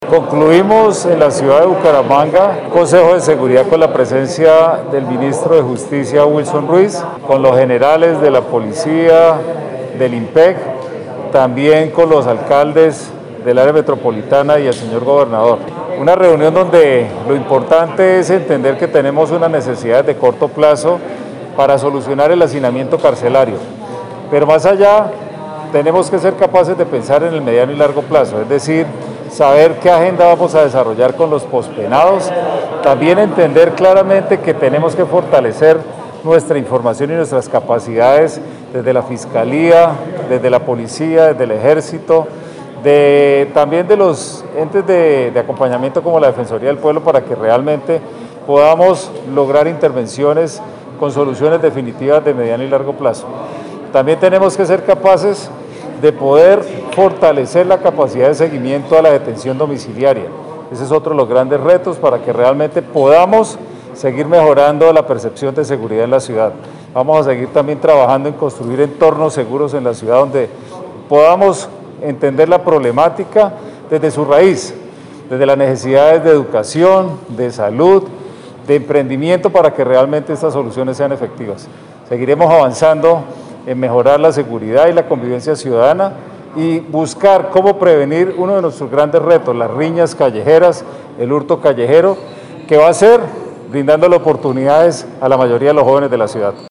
Descargar audio: Juan Carlos Cárdenas, alcalde de Bucaramanga / Wilson Ruiz Orejuela, ministro de Política Criminal y Justicia Restaurativa.
Juan-Carlos-Cardenas-alcalde-de-Bucaramanga-1.mp3